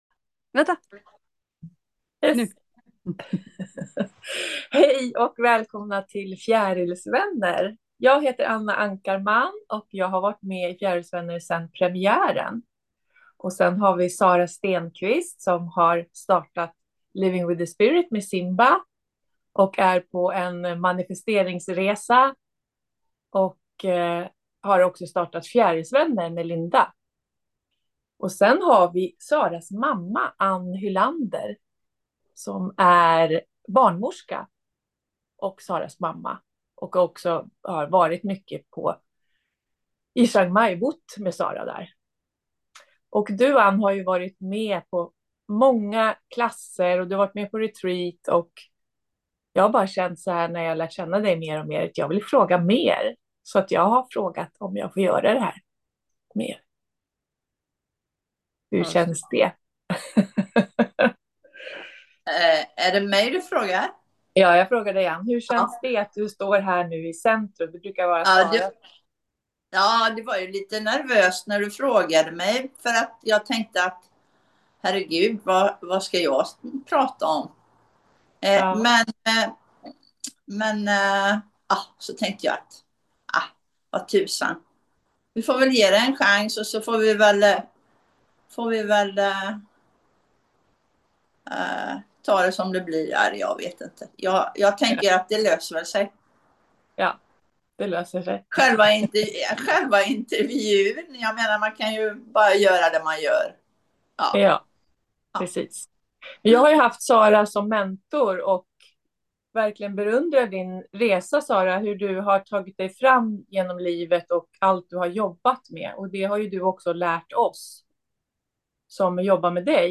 ärligt samtal om relationen mellan mor och dotter